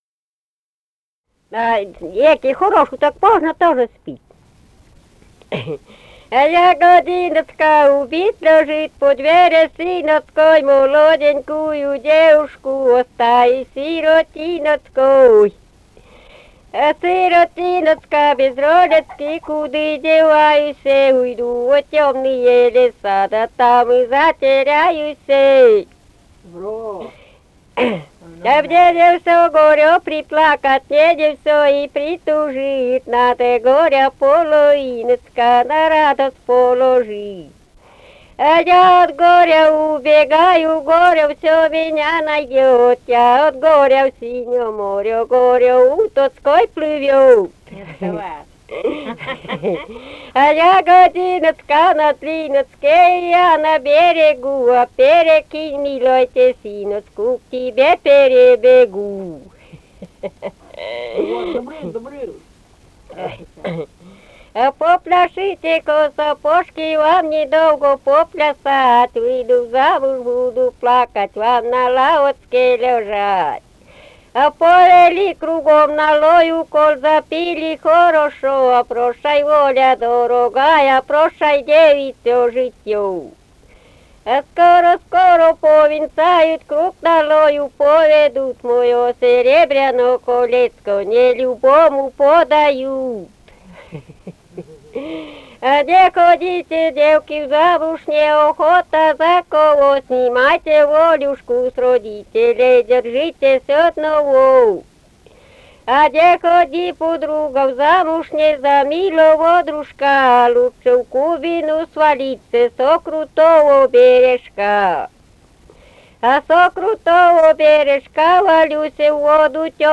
«А ягодиночка убит» (частушки).